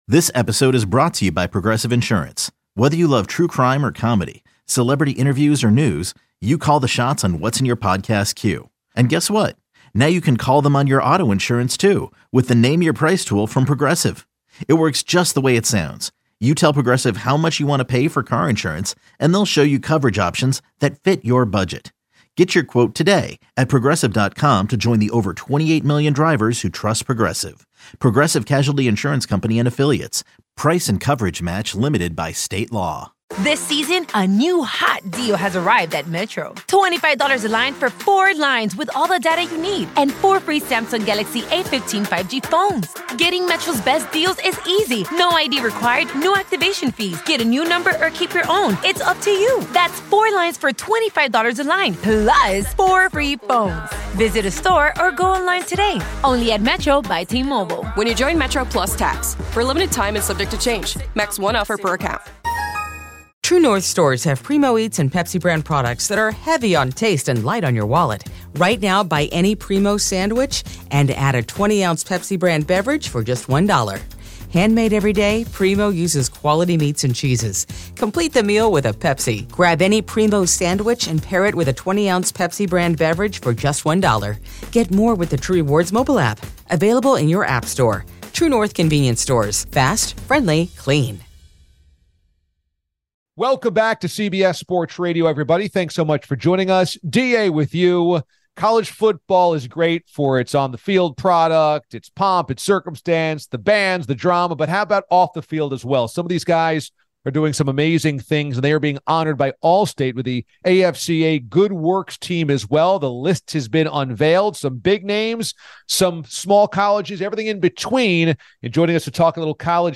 The DA Show Interviews